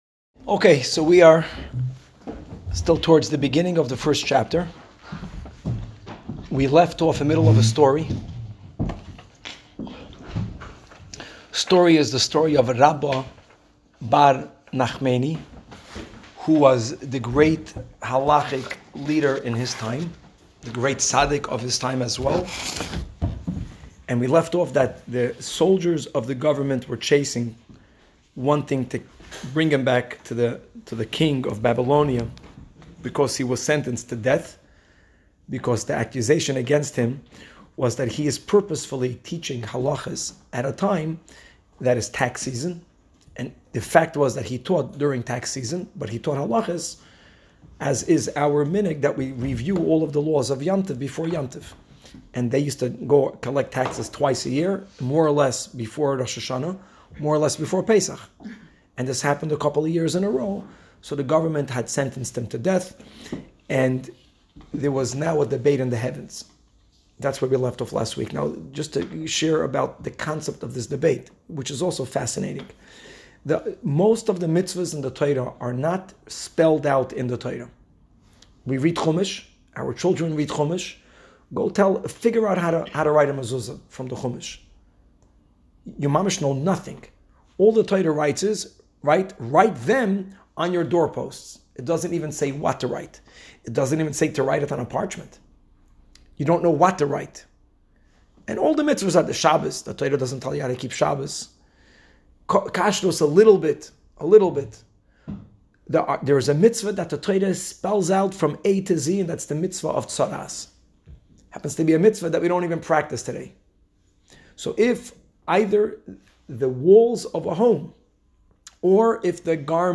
Women's Shiur